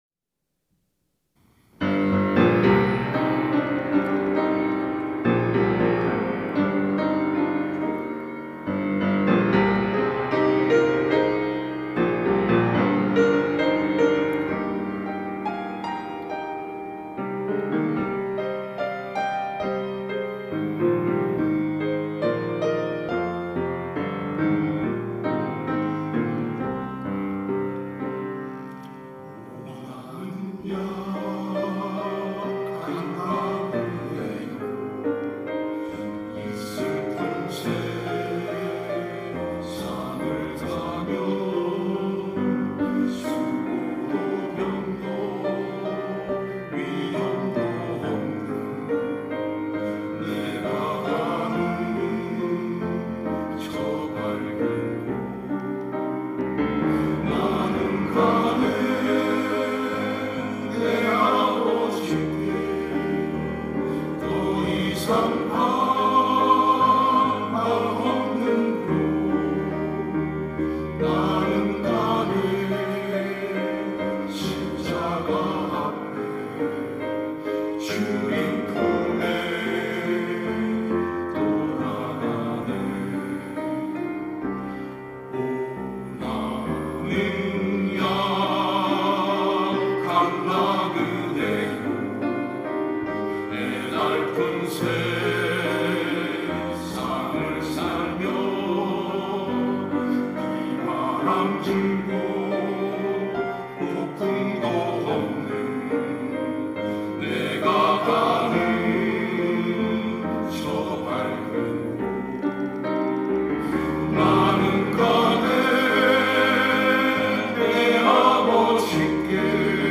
찬양대 장로